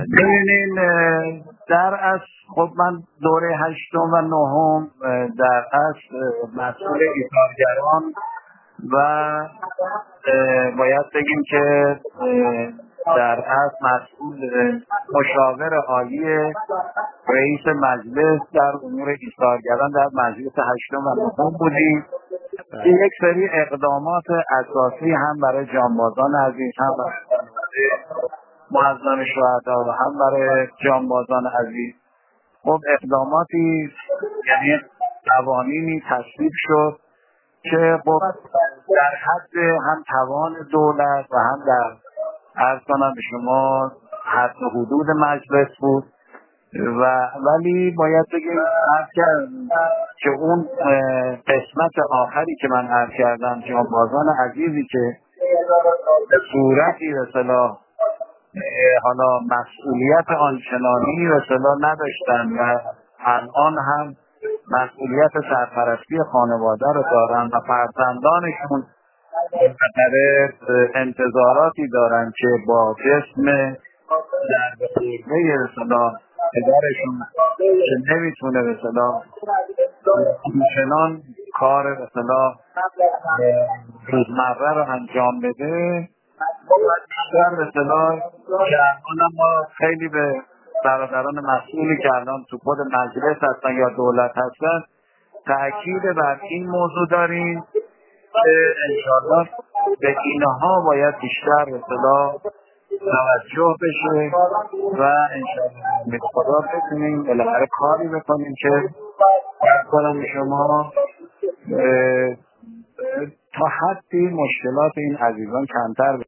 سردار کوثری در گفت‌وگو با ایکنا: